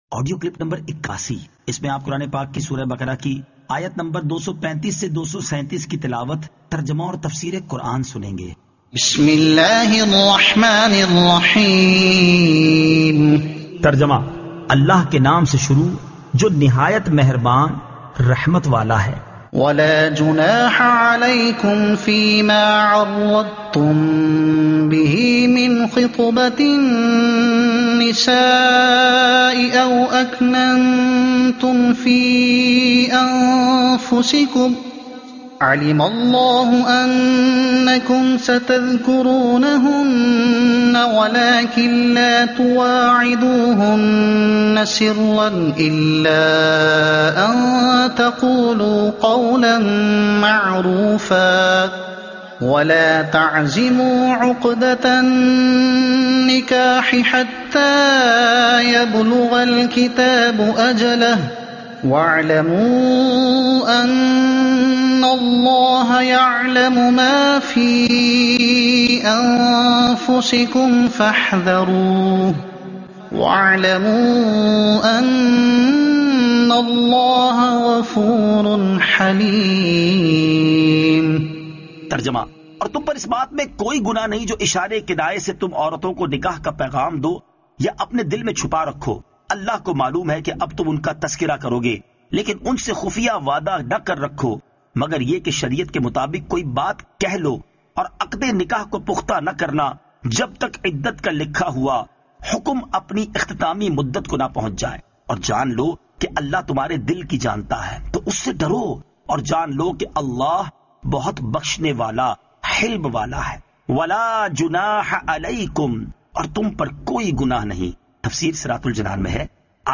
Surah Al-Baqara Ayat 235 To 237 Tilawat , Tarjuma , Tafseer